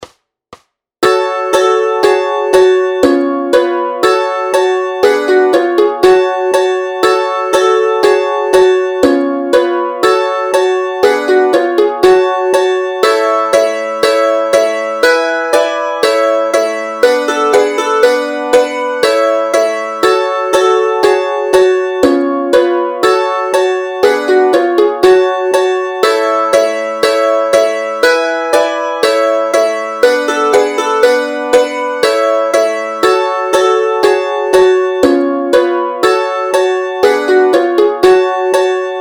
Formát Mandolínové album
Hudební žánr Lidovky